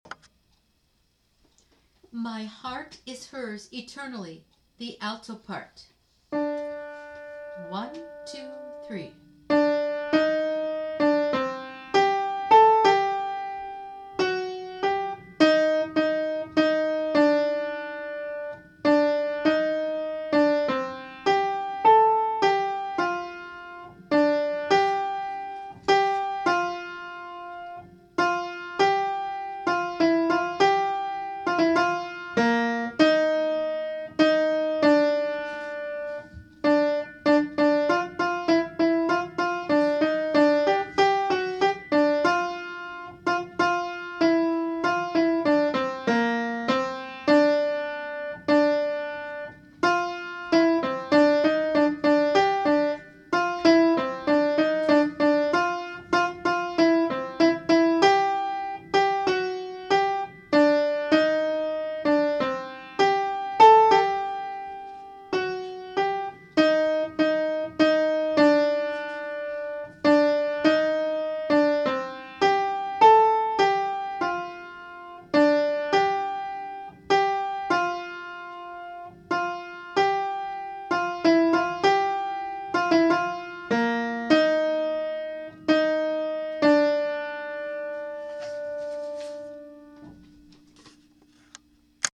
MADRIGAL AUDITIONS
02-My-Heart-is-Hers-Eternally-alto.mp3